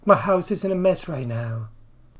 My /mis sometimes pronounced /ma/.